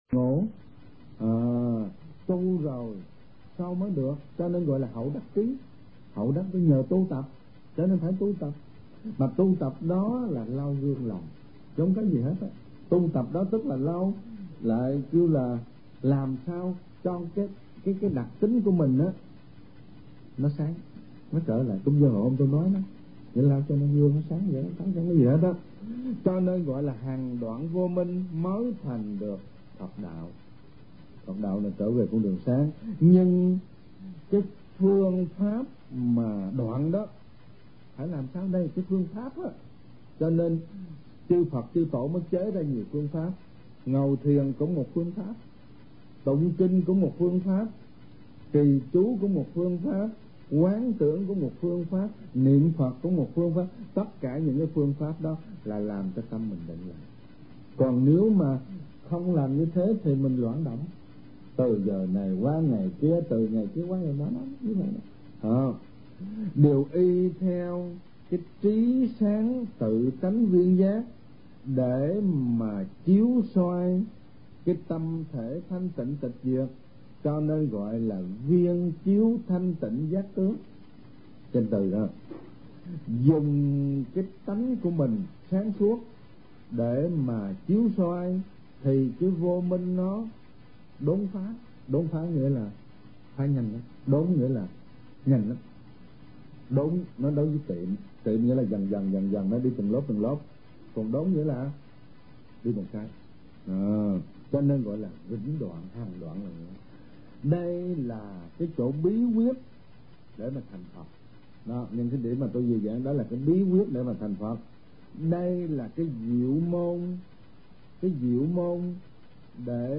Kinh Giảng